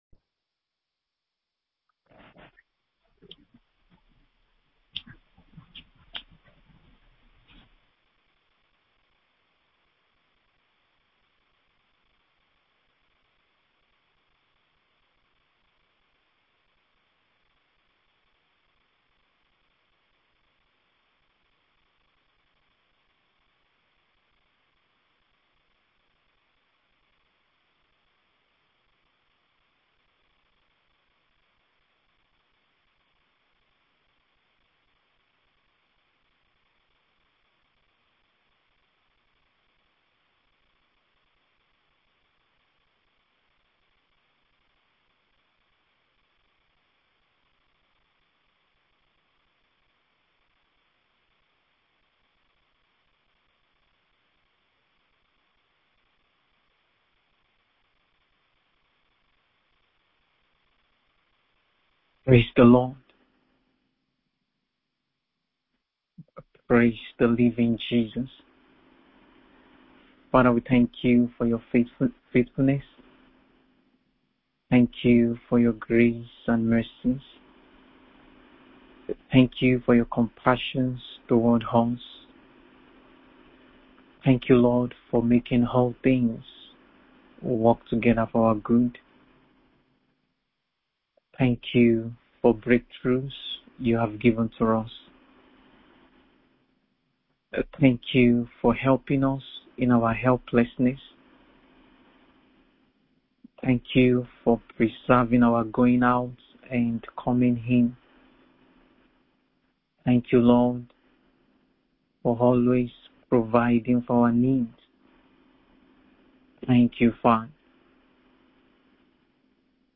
BIBLE STUDY CLASS